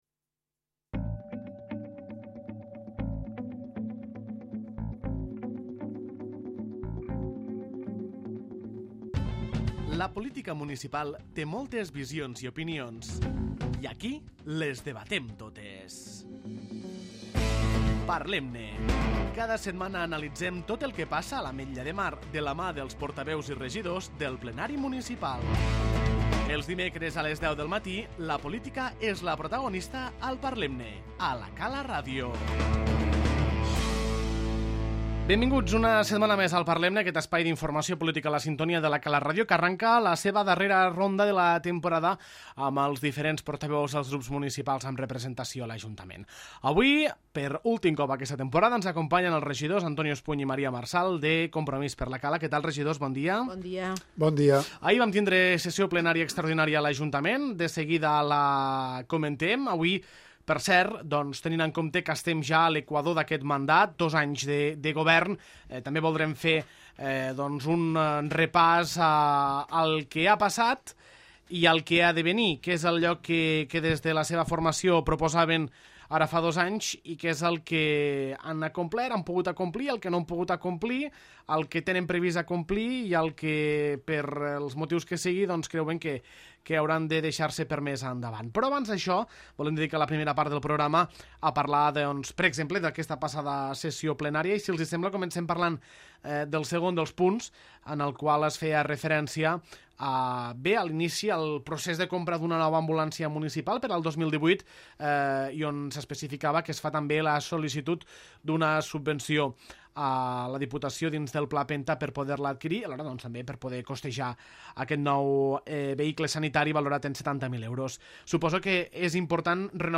Els regidors de Compromís per la Cala, Antonio Espuny i Maria Marsal, han fet un repàs a l'actualitat municipal i també han fet balanç d'aquests dos anys de mandat en el govern local.